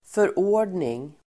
Uttal: [för'å:r_dning]